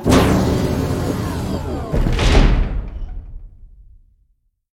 phaseClose.wav